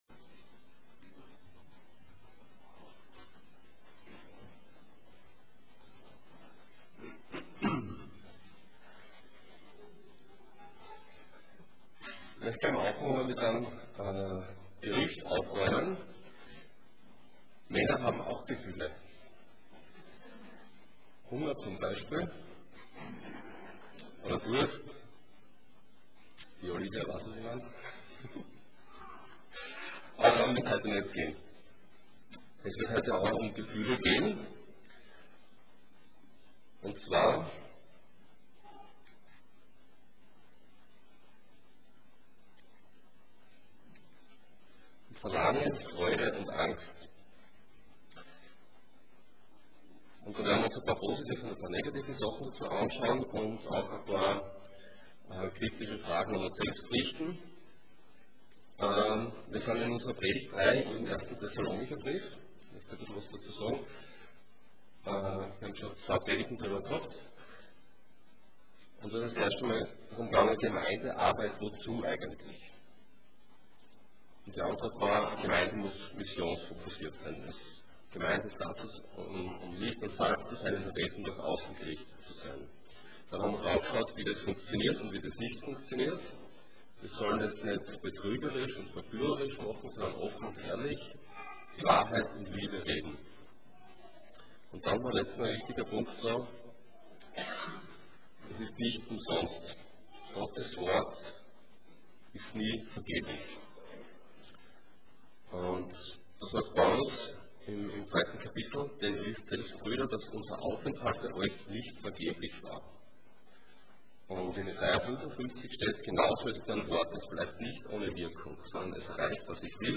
Passage: 1 Thessalonians 2:17-3:5 Dienstart: Sonntag Morgen